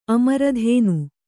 ♪ amaradhēnu